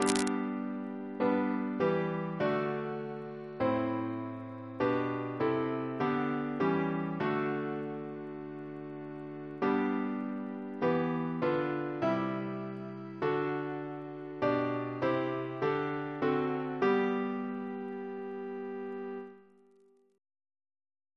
Double chant in G Composer: Albert Herbert Brewer (1865-1928)